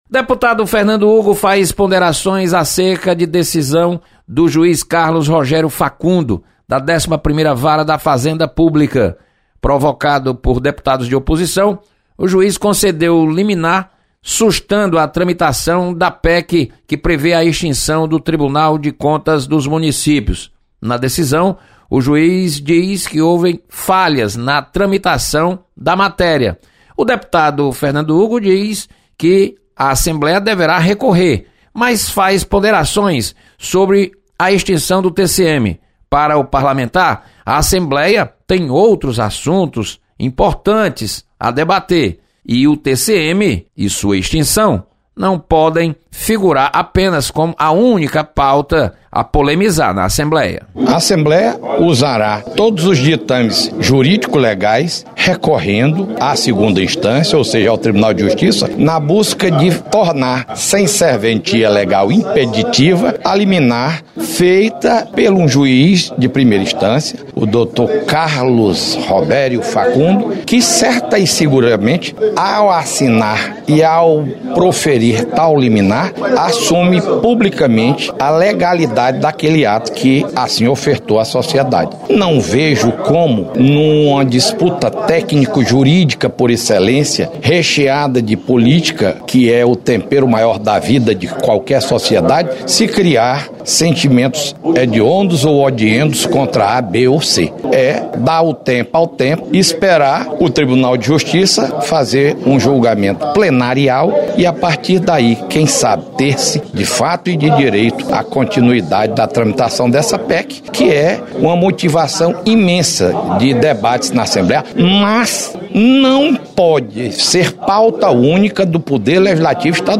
Deputados comentam sobre decisão de juiz que mandou sustar tramitação da PEC que visa extinção do TCM.